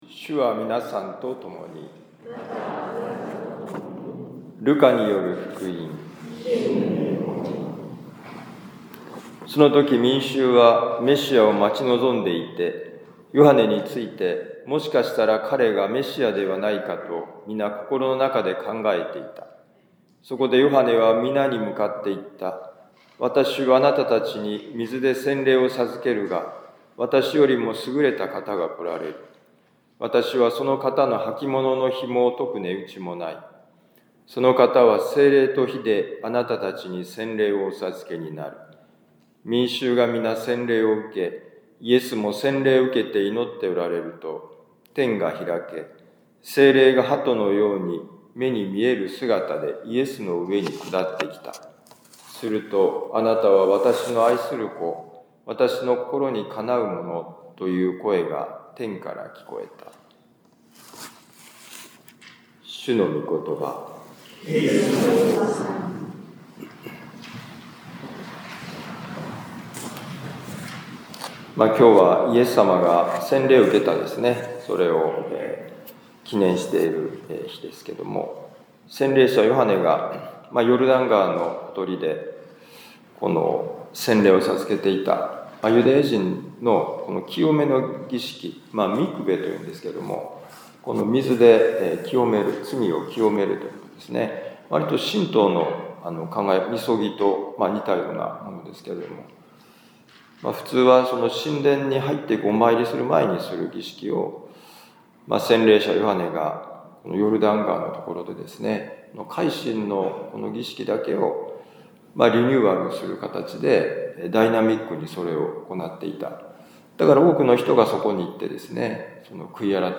【ミサ説教】
ルカ福音書3章15-16、21-22節「手を放す、軽くなる、満ちていく」2025年1月12日主の洗礼のミサ六甲カトリック教会